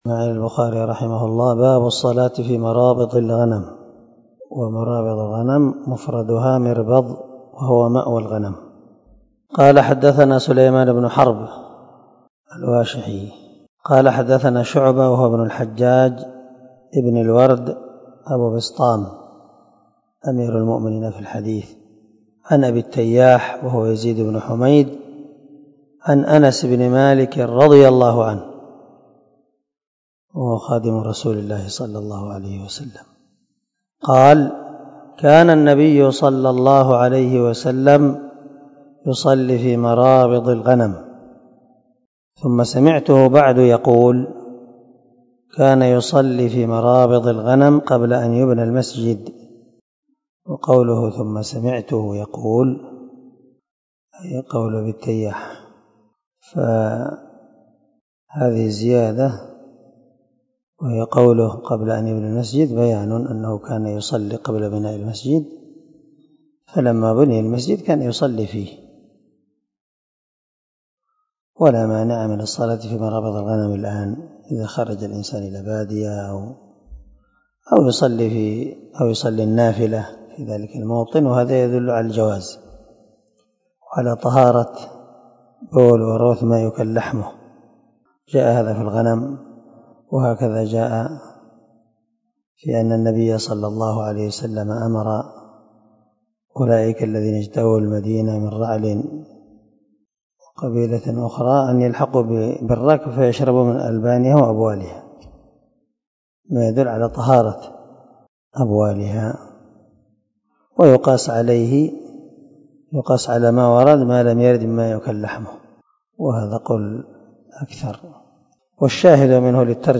321الدرس 54 من شرح كتاب الصلاة حديث رقم ( 429 – 430 ) من صحيح البخاري
دار الحديث- المَحاوِلة- الصبيحة.